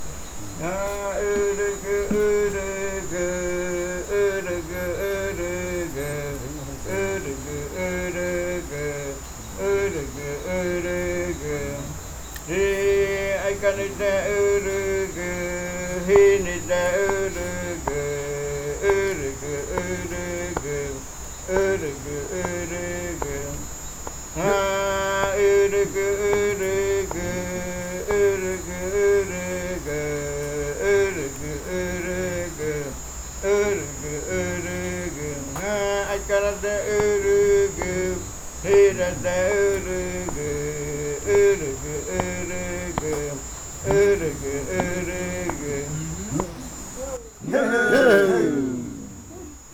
Leticia, Amazonas, (Colombia)
Grupo de danza Kaɨ Komuiya Uai
Canto fakariya de la variante Muruikɨ (cantos de la parte de arriba) Esta grabación hace parte de una colección resultante del trabajo de investigación propia del grupo de danza Kaɨ Komuiya Uai (Leticia) sobre flautas y cantos de fakariya.
Fakariya chant of the Muruikɨ variant (Upriver chants) This recording is part of a collection resulting from the Kaɨ Komuiya Uai (Leticia) dance group's own research on pan flutes and fakariya chants.